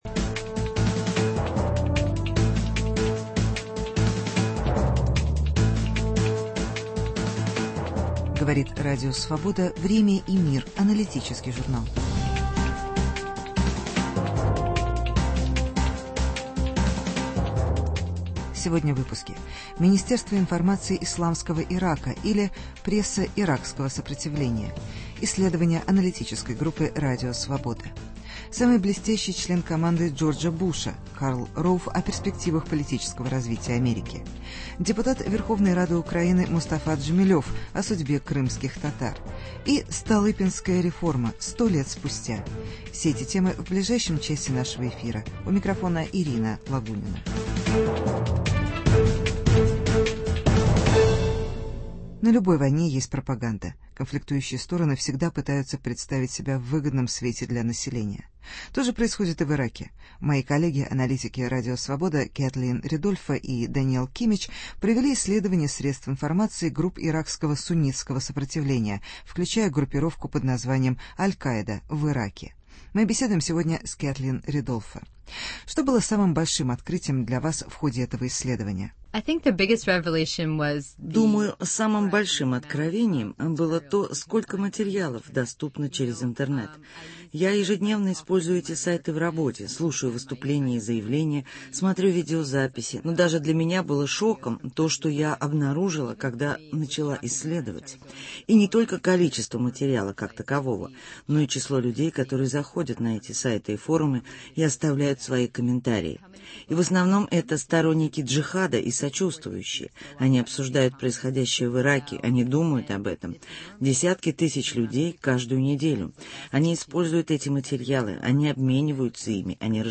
Проблемы крымских татар. Интервью с Мустафой Джемилевым.